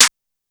JJSnares.wav